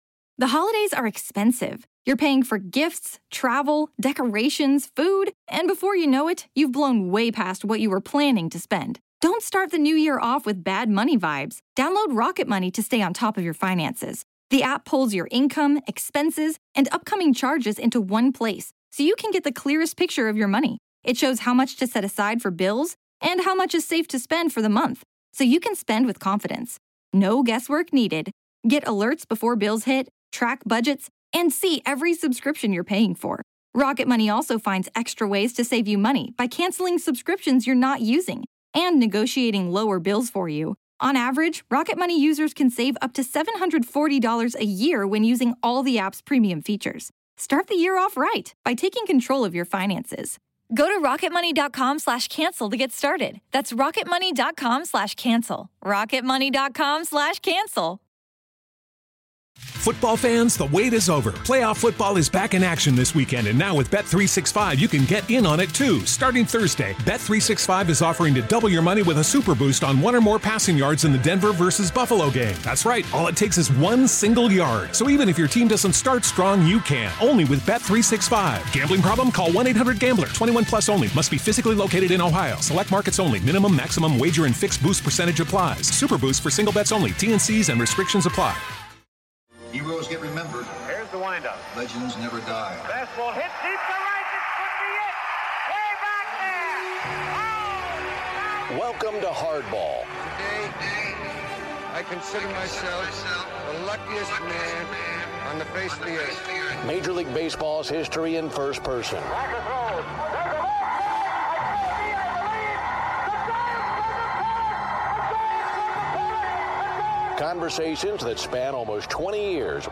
This episode is a conversation with Hall of Famer and true Broadcast Legend VIN Scully. From his childhood NY roots to his first broadcasting break (a football game!) through some of his memorable days and nights at ballparks across America.